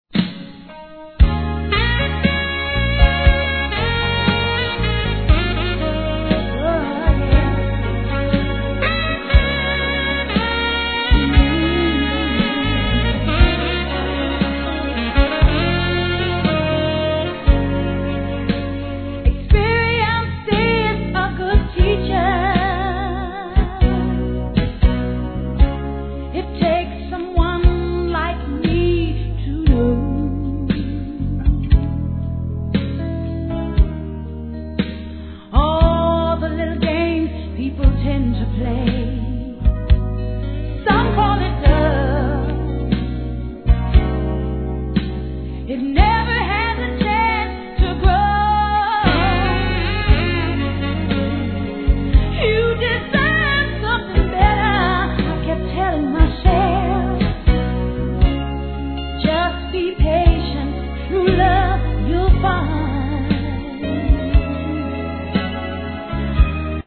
HIP HOP/R&B
極上バラード